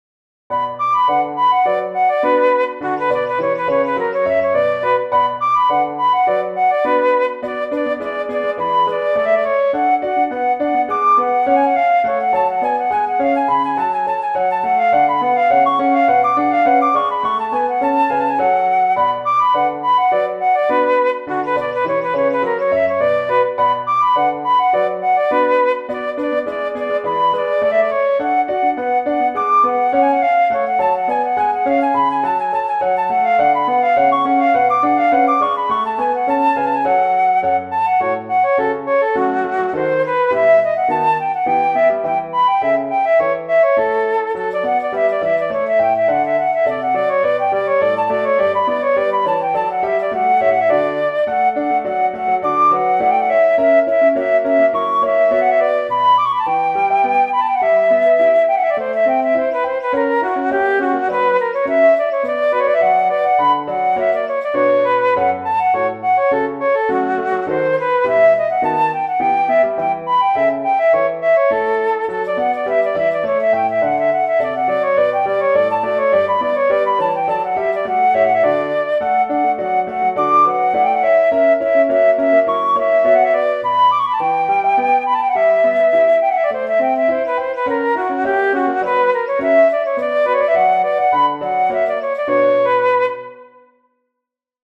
arranged for Flute and Piano